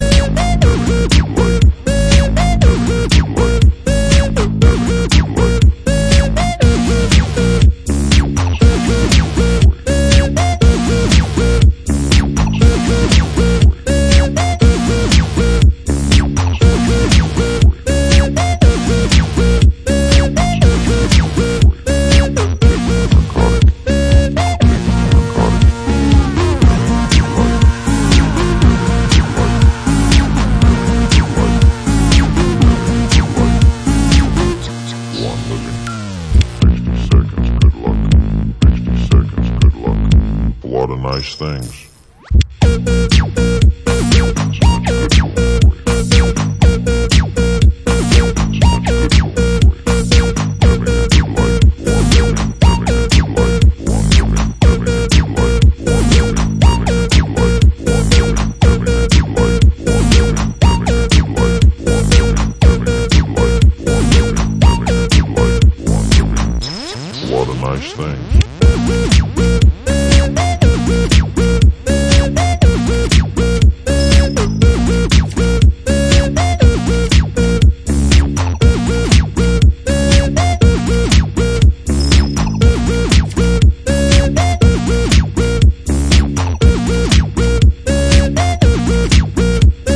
Pornotrance from outta space!